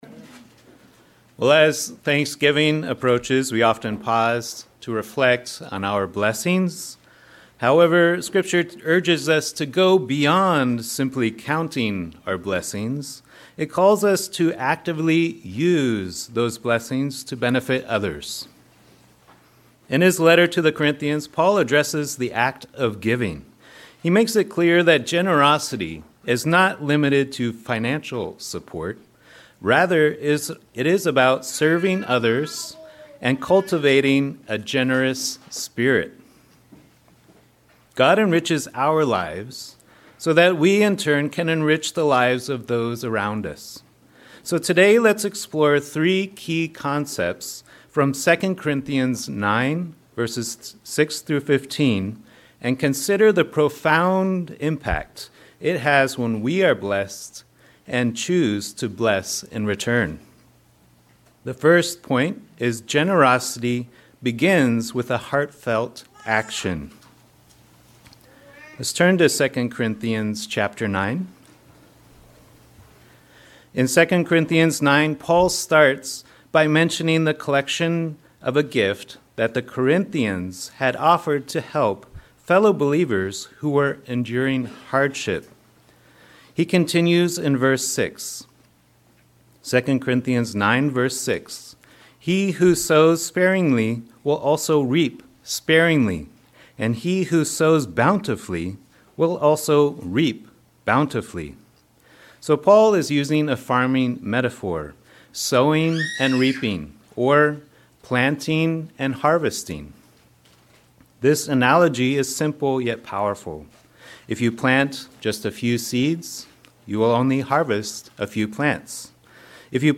Given in Northern Virginia